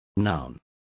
Complete with pronunciation of the translation of noun.